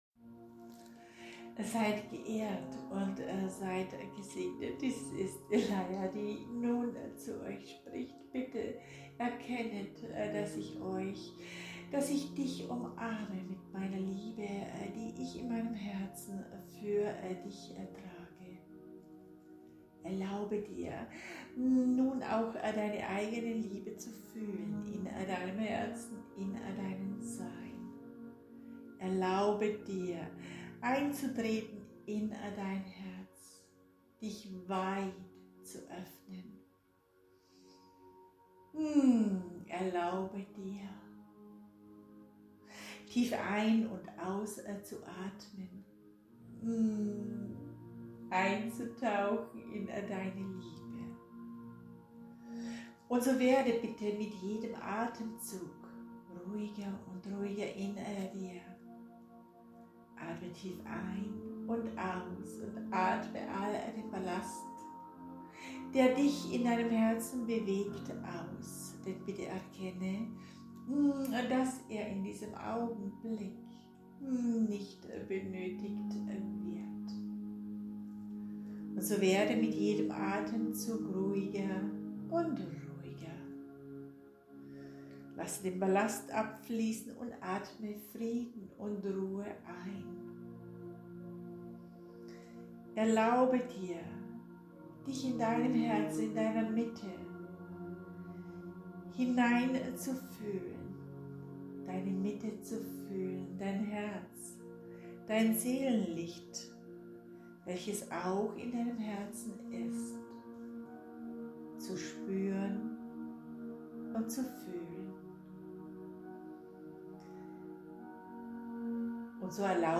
Heilungs-Meditationen